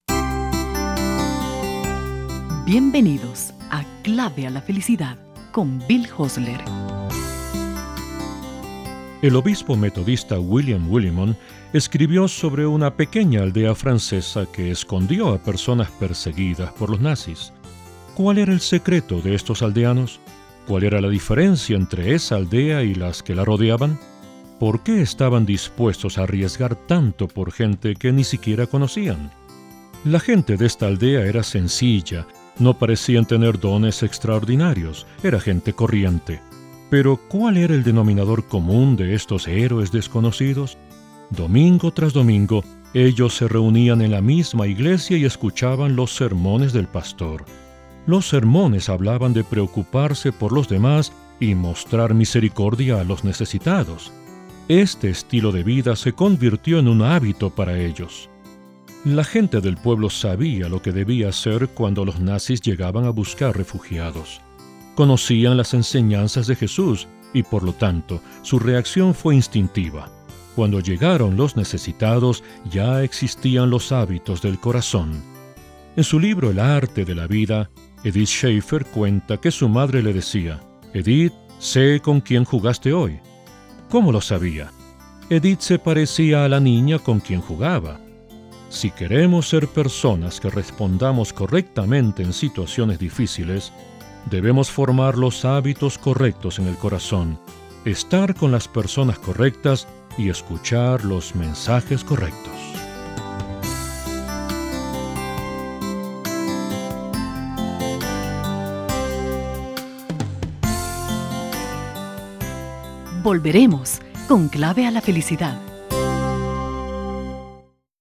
Clave a la Felicidad es traducida, doblada y producida en estudios en Quito, Ecuador.